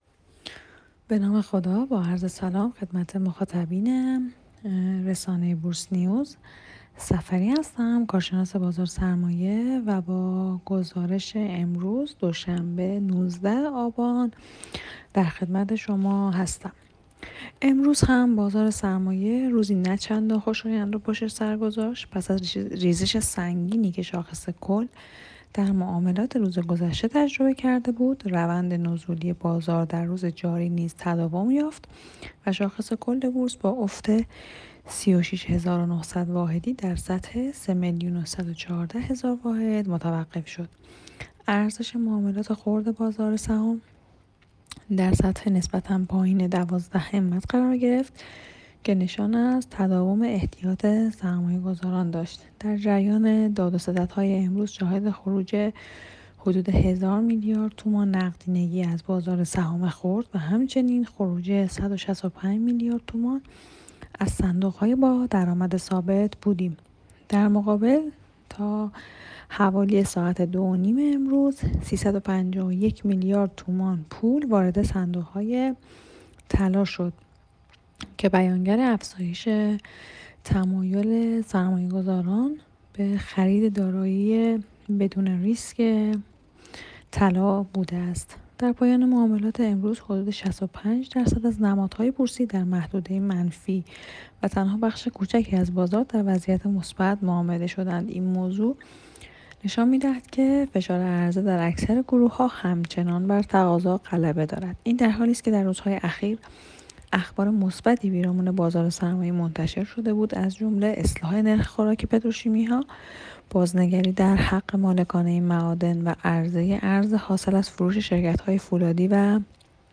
در گفت‌و‌گو با بورس نیوز